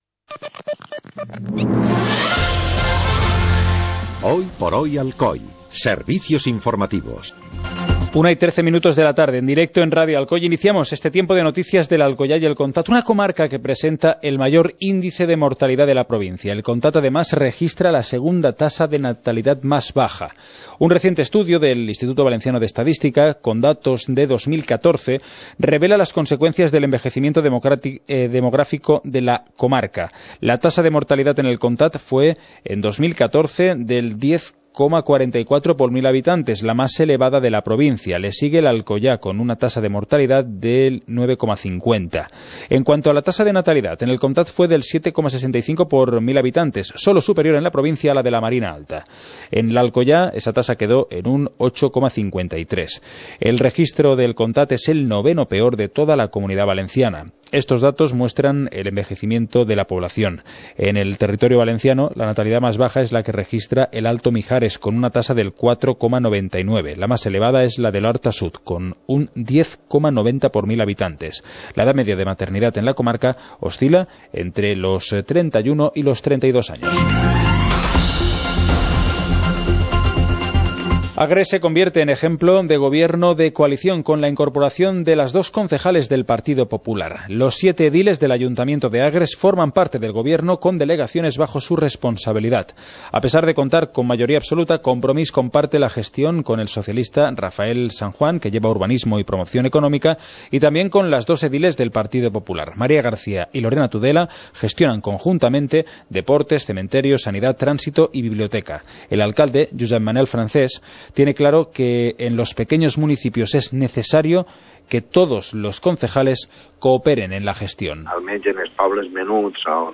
Informativo comarcal - martes, 12 de abril de 2016